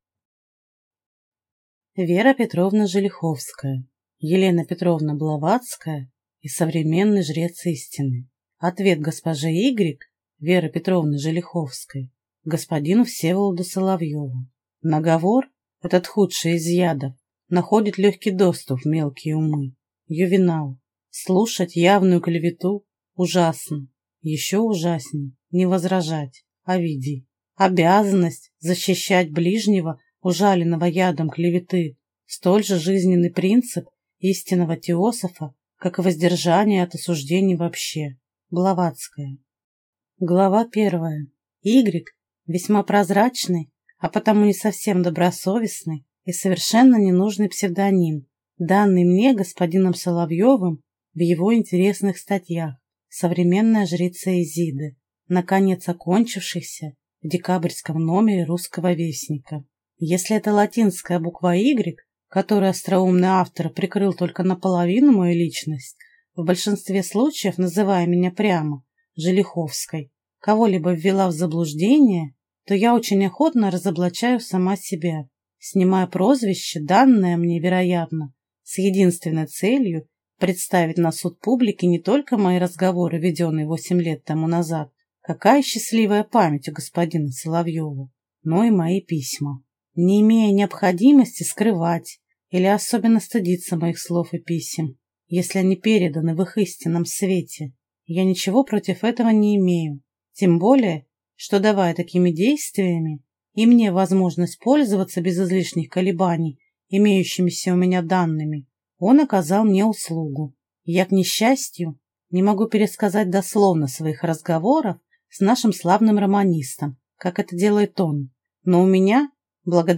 Аудиокнига Е.П.Блаватская и современный жрец истины | Библиотека аудиокниг